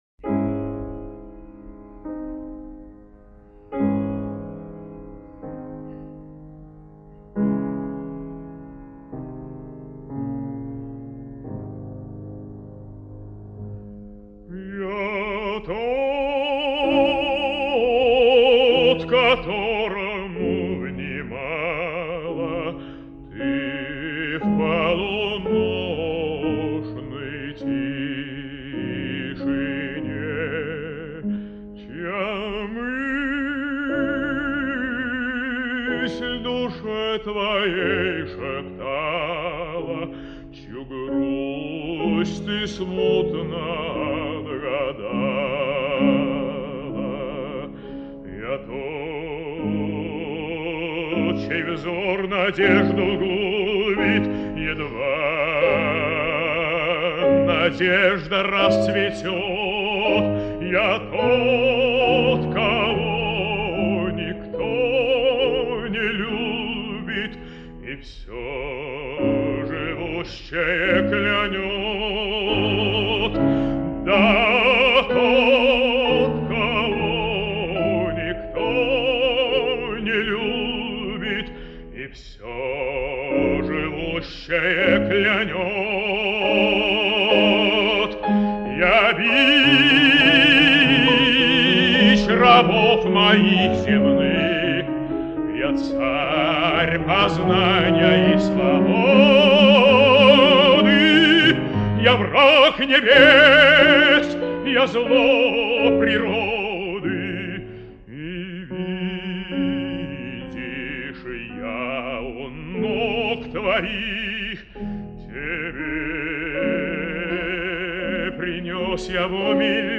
А. Рубинштейн - Ария Демона "Я тот, которому внимала ты..."
Георг Отс – Ария Демона "Я тот, которому внимала ты..." (А. Рубинштейн)